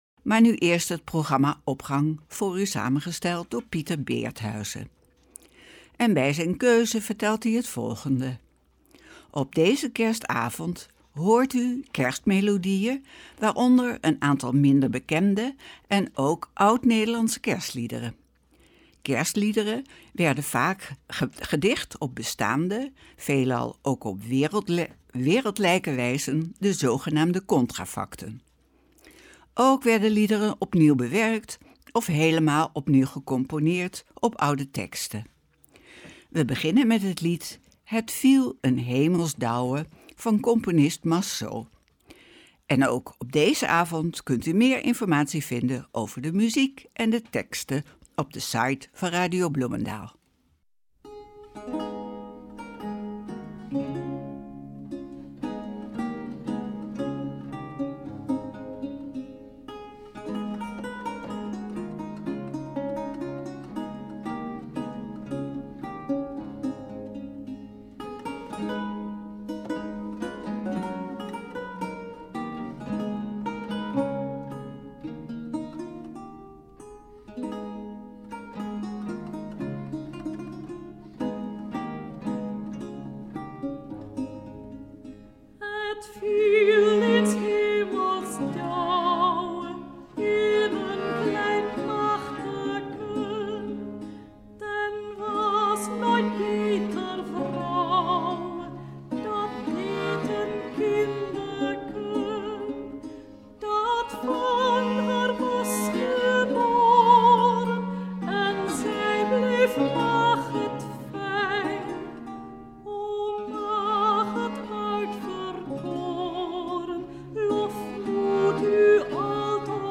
Opening van deze Kerstnachtdienst met muziek, rechtstreeks vanuit onze studio.
Op deze kerstavond hoort u Kerstmelodieën, waaronder een aantal minder bekende, en ook oud Nederlandse Kerstliederen. Deze liederen werden vaak gedicht op bestaande, veelal ook wereldlijke wijzen (zgn.contrafacten).